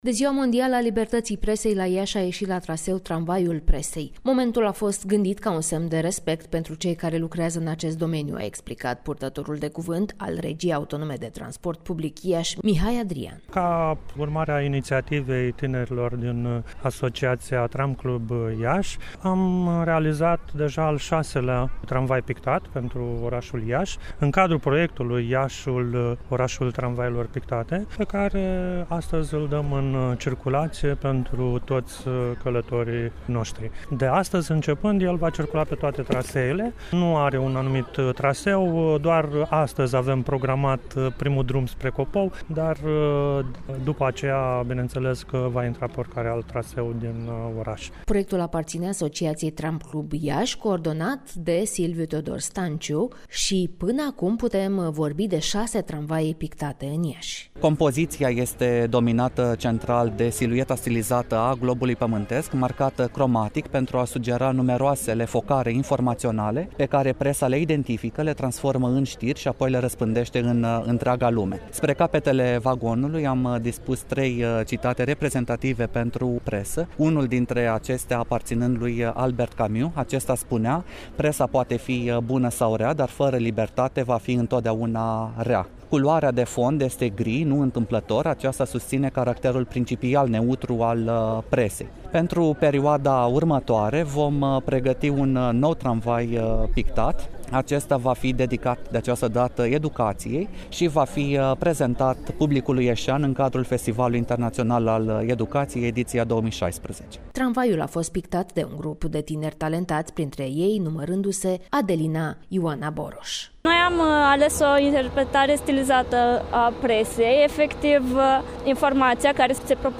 a realizat un radioreportaj pe acest subiect